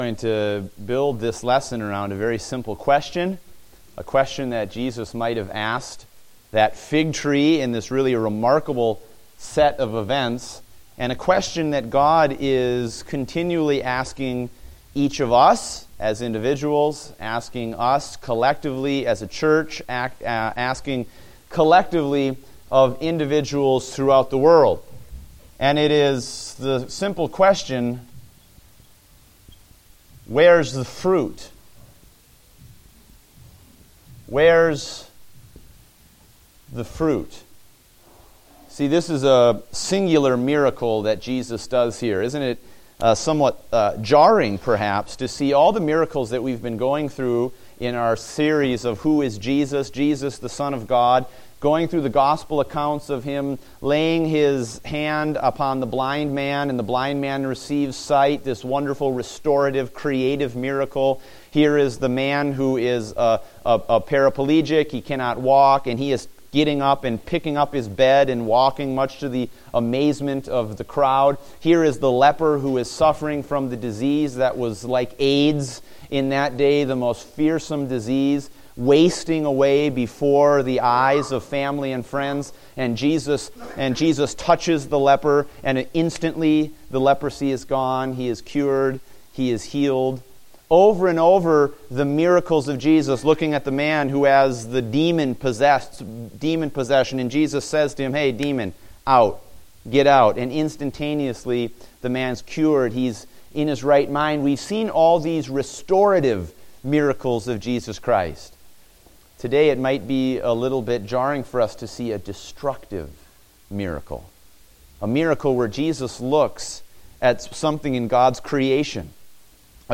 Date: February 28, 2016 (Adult Sunday School)